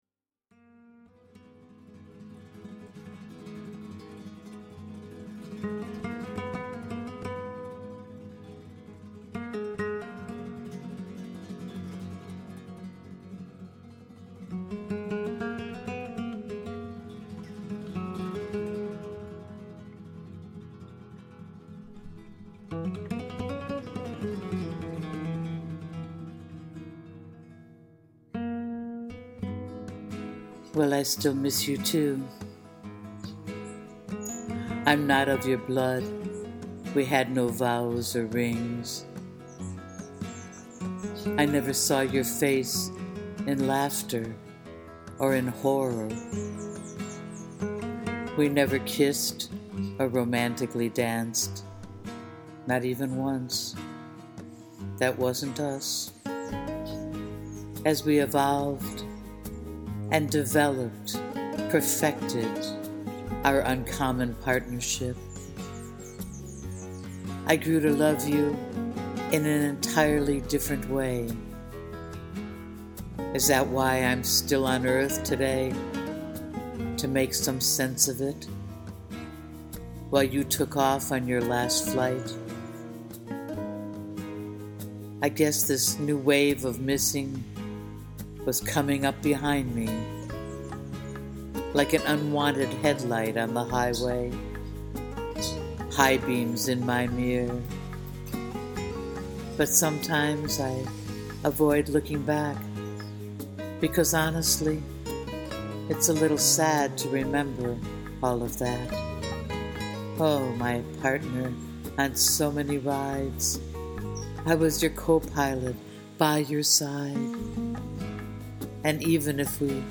Please click Play to hear my recorded version of this poem to some Arabic/Spanish music